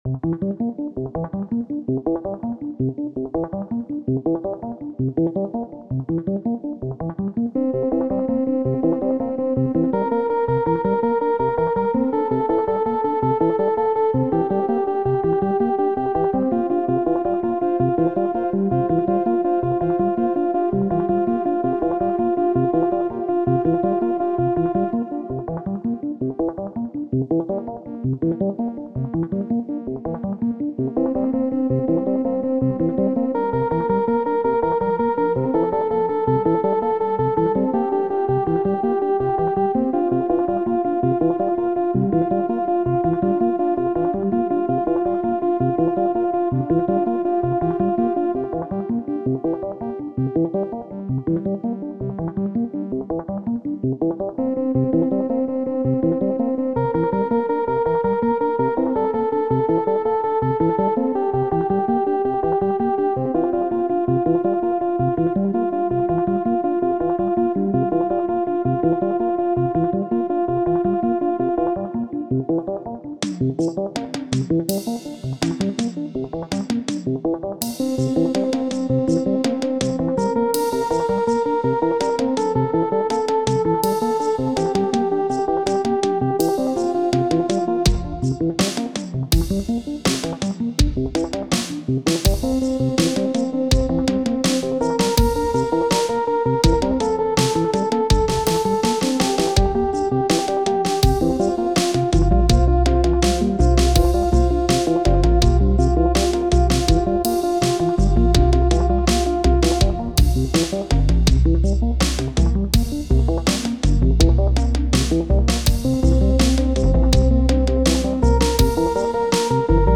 Instrument rack with arpeggiator. First loop has five 1/16th notes so it cycles, other loops are 2 against 3.